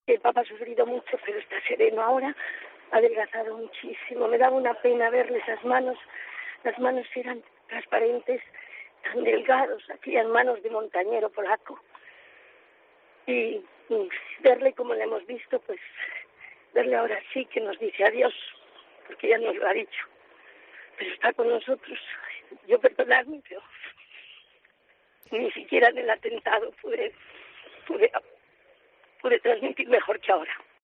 Horas antes de anunciarse la muerte del Papa polaco el 2 de abril de 2005, la corresponsal de COPE en el Vaticano desvelaba cómo fue su despedida con el Pontífice
Una emocionada y compungida Gómez Borrero recordaba que era uno de los momentos más duros para ella, junto al día que tuvo que informar del atentado frustrado que sufrió el Papa el 13 de mayo de 1981 cuando Mehmet Ali A?ca disparó cuatro veces contra él mientras estaba en la plaza del Vaticano.